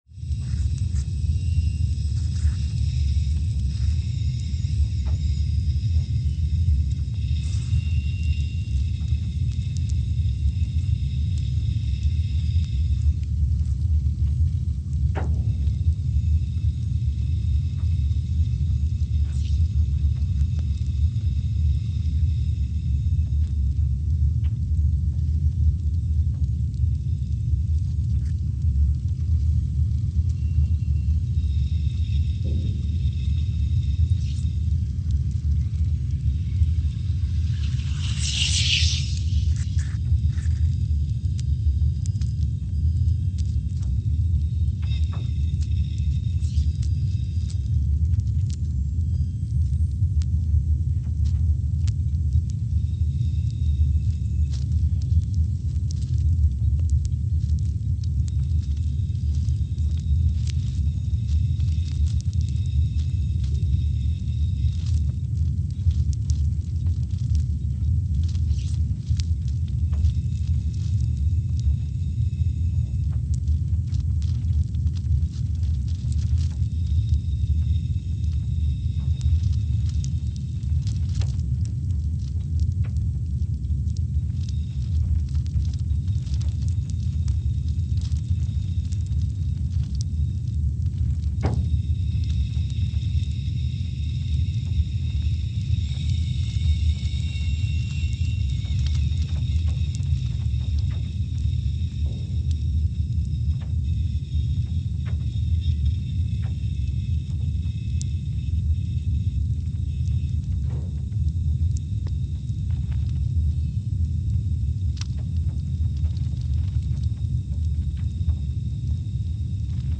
Scott Base, Antarctica (seismic) archived on June 29, 2021
No events.
Station : SBA (network: IRIS/USGS) at Scott Base, Antarctica
Sensor : CMG3-T
Speedup : ×500 (transposed up about 9 octaves)
SoX post-processing : highpass -2 90 highpass -2 90